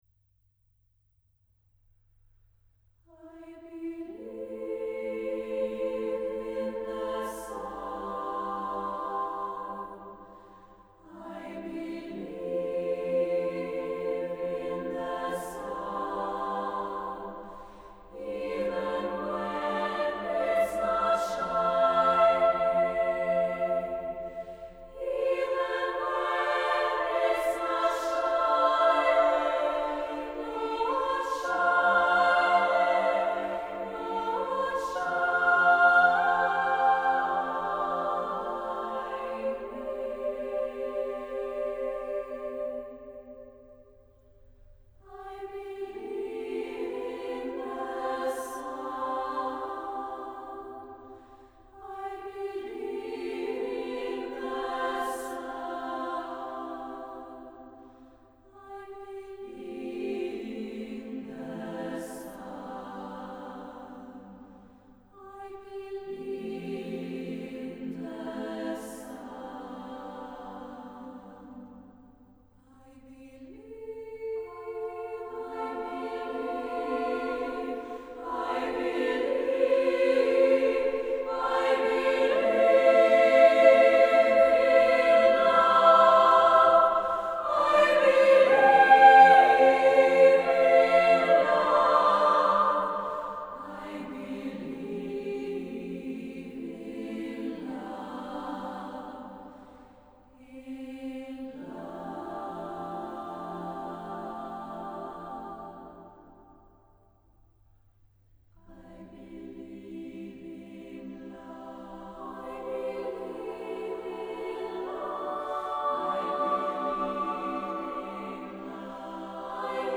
Accompaniment:      A Cappella
Music Category:      Choral
Beautifully melodic, with rich harmonic texture